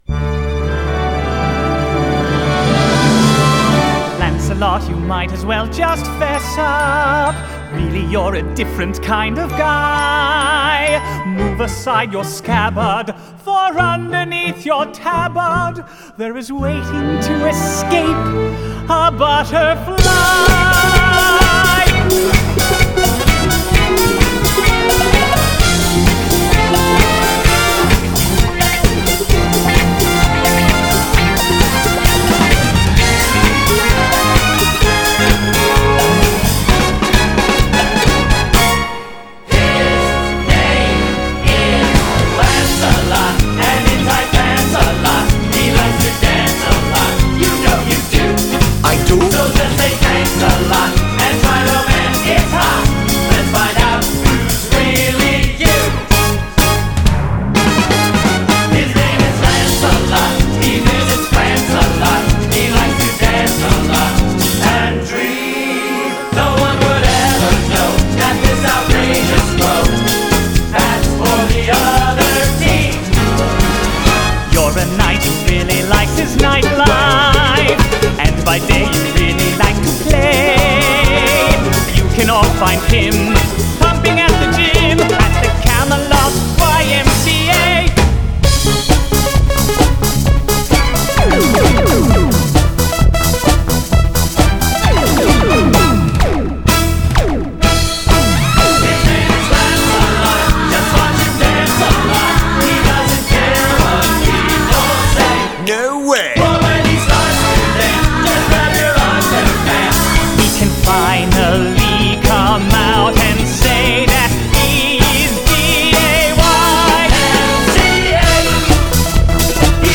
Genre: Musical.